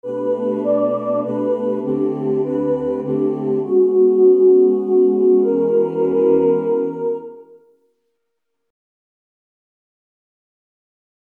Key written in: E Major
Other part 2: